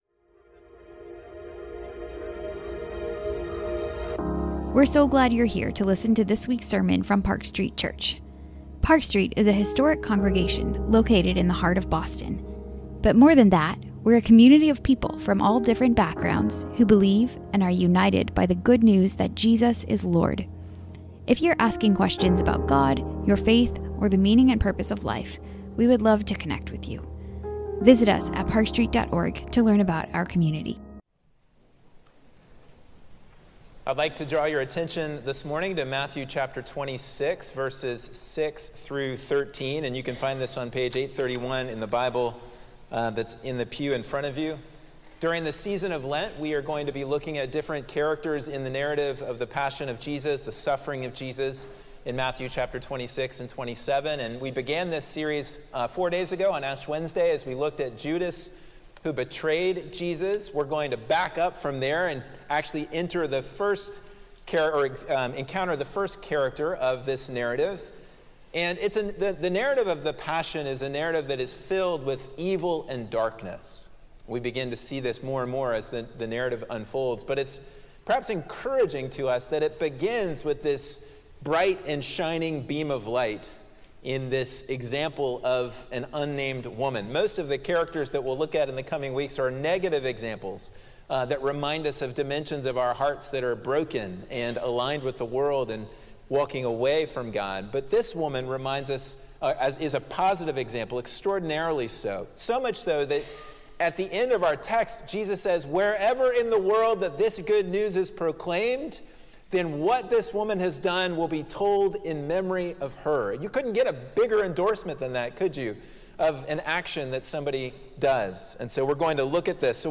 Sermons | Park Street Church